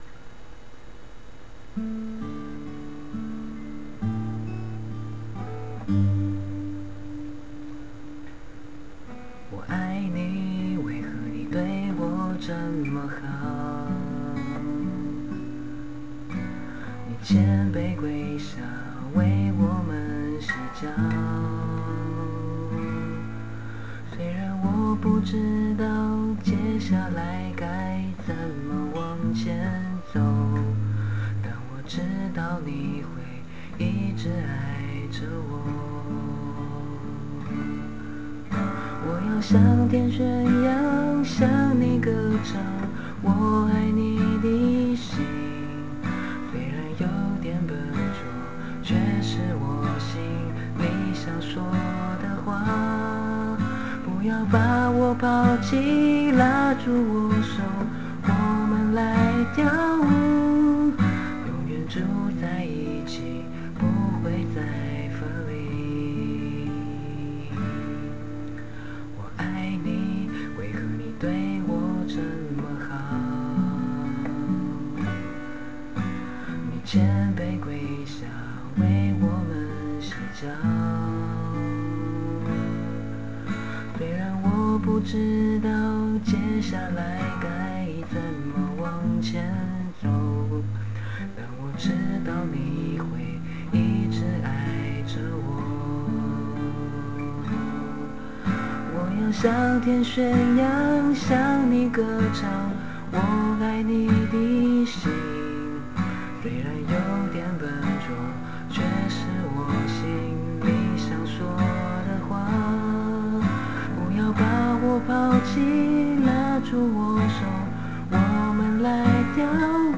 录音品质有点差 因为是用mp3录的 但就请多包涵^^